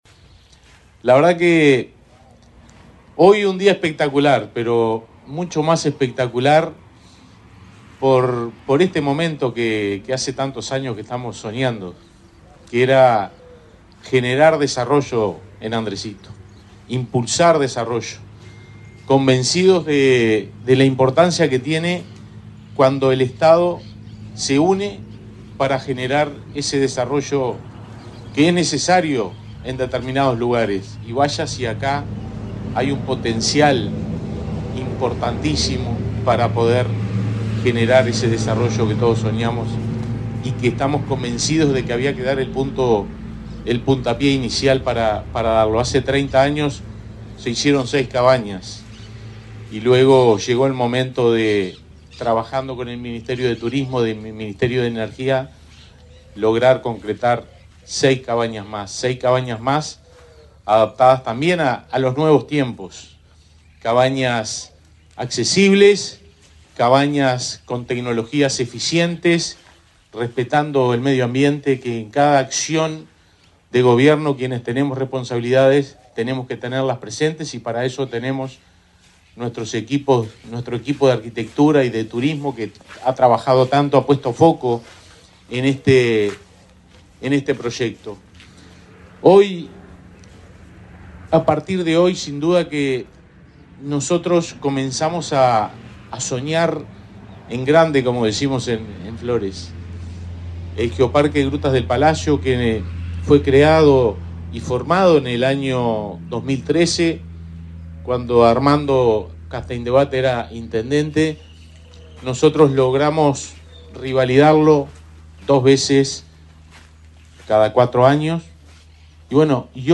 Palabras de autoridades en acto en Flores
El intendente Fernando Echeverría y el ministro Eduardo Sanguinetti destacaron la importancia de esta nueva infraestructura para el sector turístico.